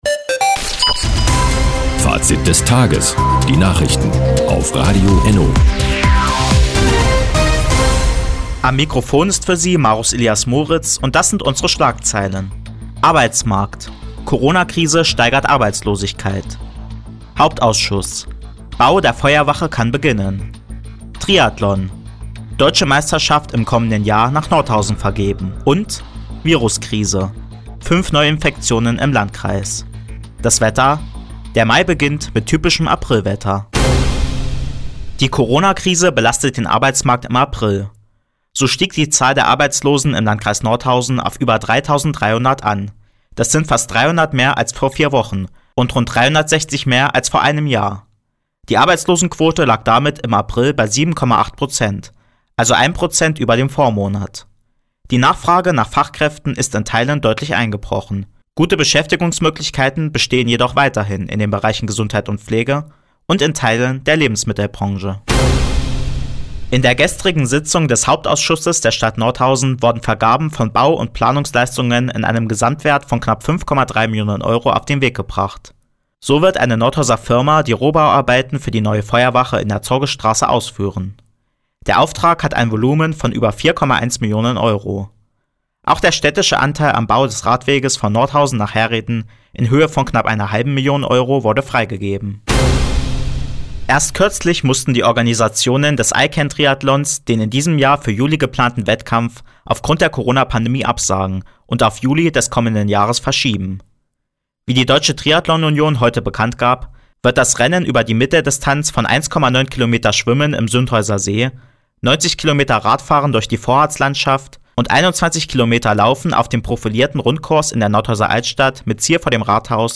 Do, 17:06 Uhr 30.04.2020 Neues von Radio ENNO Fazit des Tages Anzeige Refinery (lang) Seit Jahren kooperieren die Nordthüringer Online-Zeitungen und das Nordhäuser Bürgerradio ENNO. Die tägliche Nachrichtensendung ist jetzt hier zu hören.